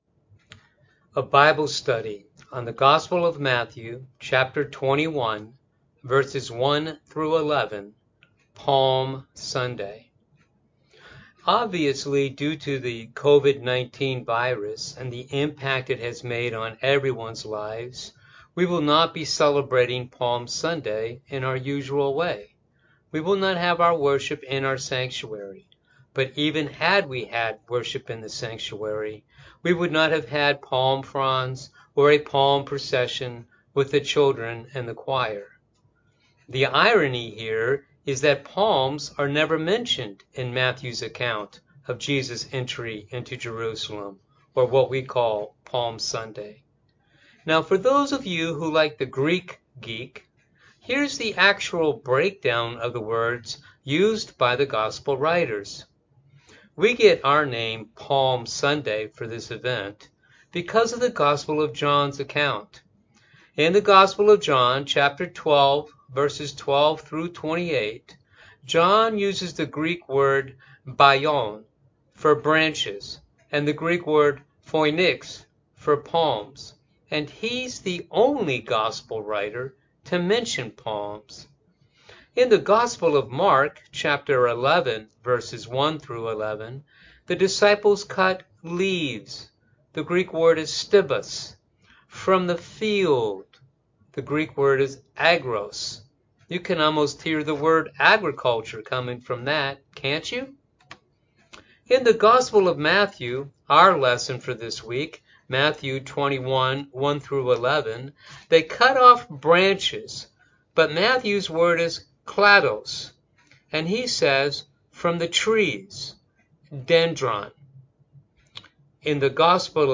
Online Bible Study # 3 – Palm Sunday Sermon